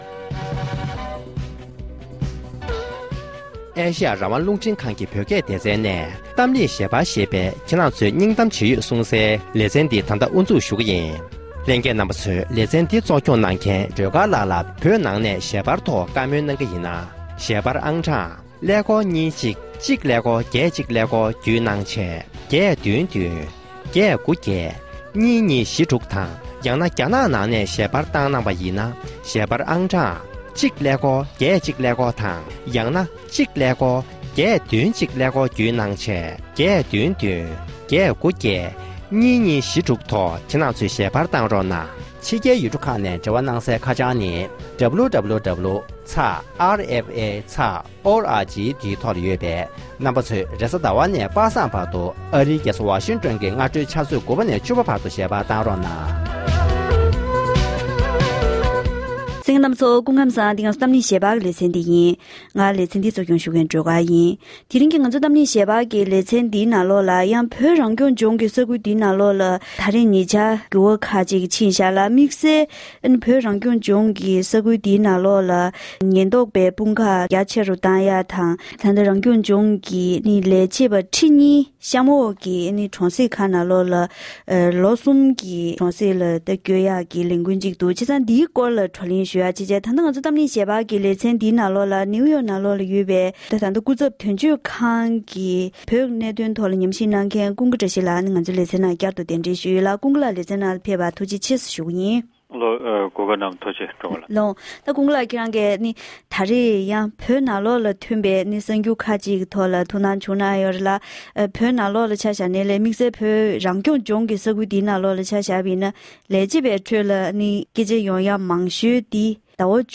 གཏམ་གླེང་ཞལ་པར་